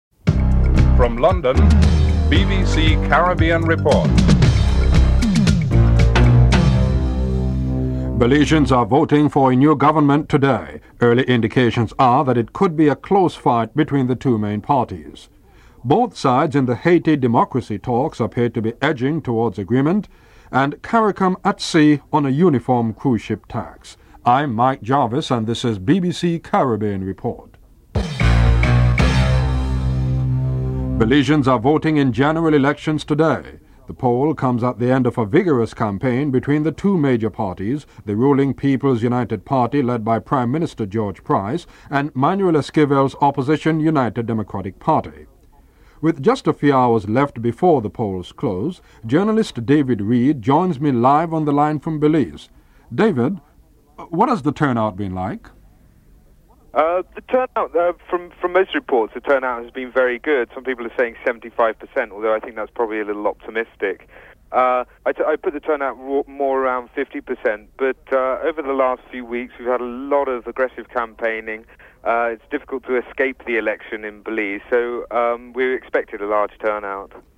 1. Headlines (00:00-00:38)
Interview with Carlyle Dunkley, Minister of State responsible for Tourism, Jamaica (08:51-12:00)